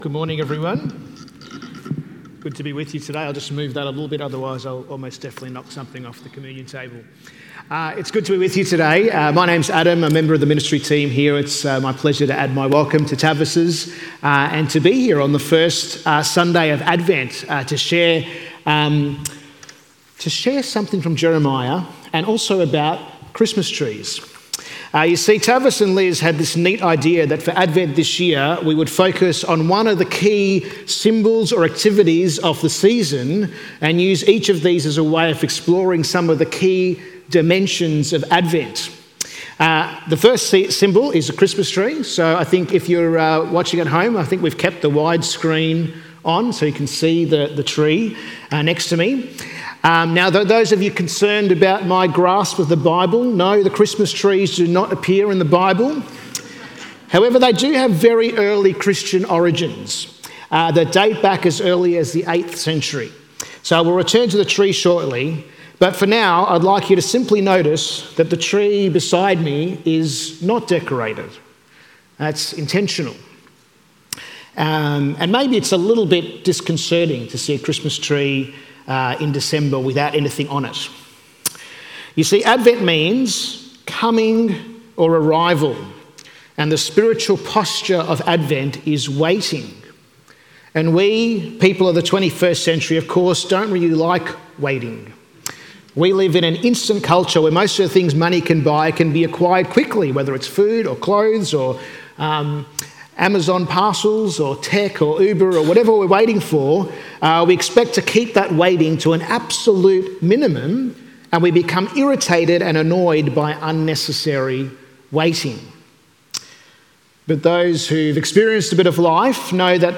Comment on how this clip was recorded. at 10am at Kew Campus